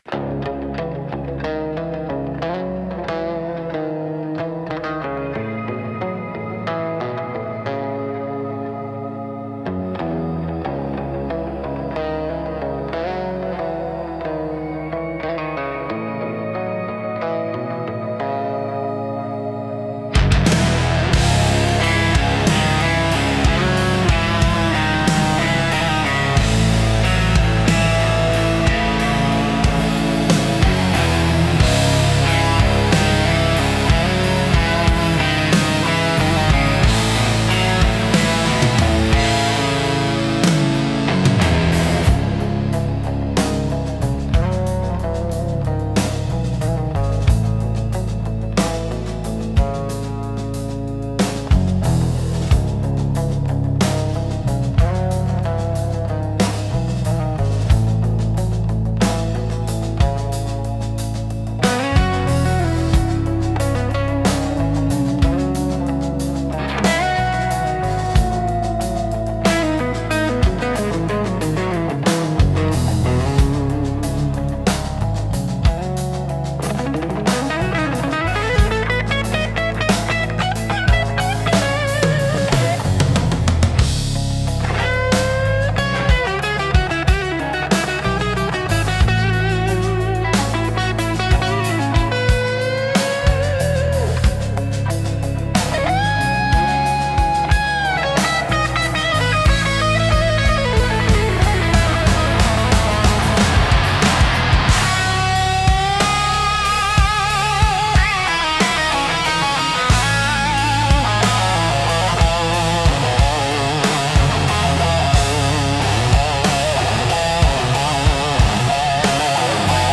Instrumental - Hauled on Steel Strings (1)